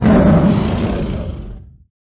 082-Monster04.mp3